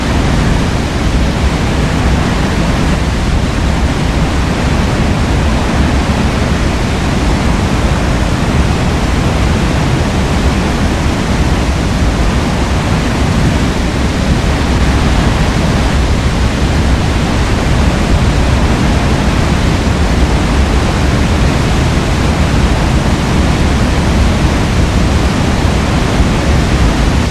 6b-niagara-waters-roaring.mp3